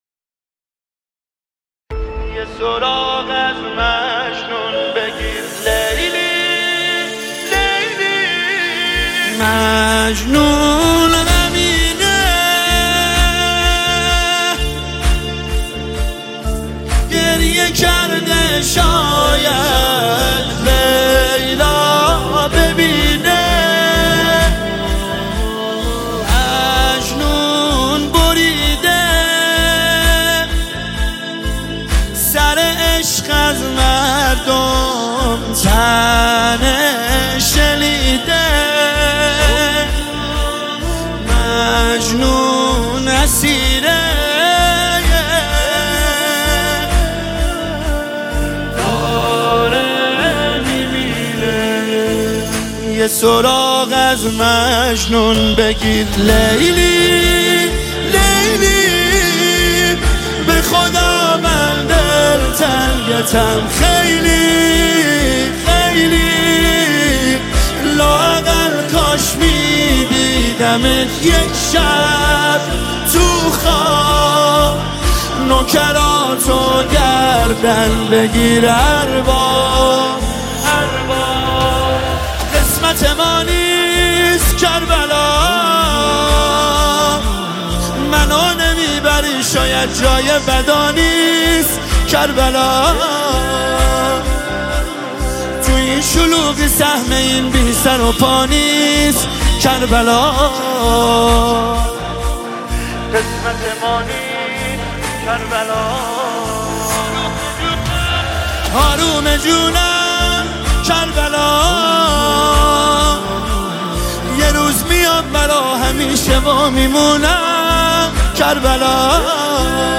نماهنگ دلنشین
مداحی اربعین